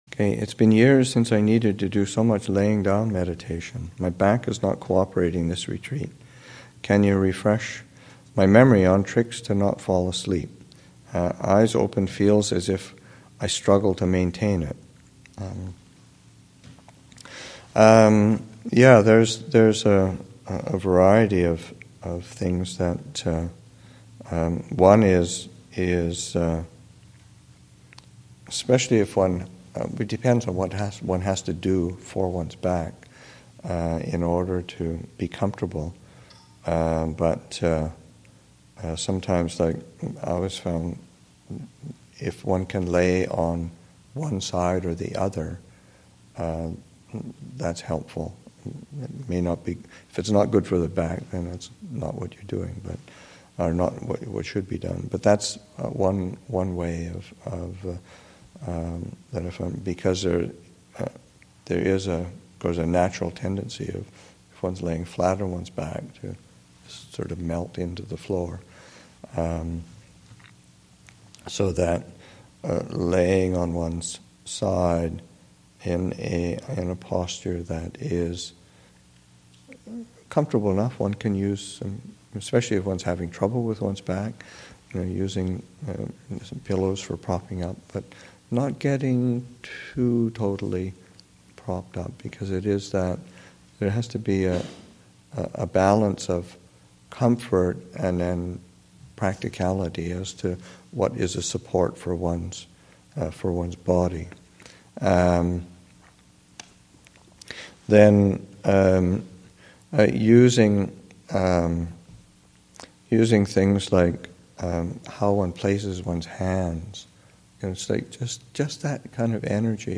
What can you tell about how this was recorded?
2015 Thanksgiving Monastic Retreat, Session 1 – Nov. 21, 2015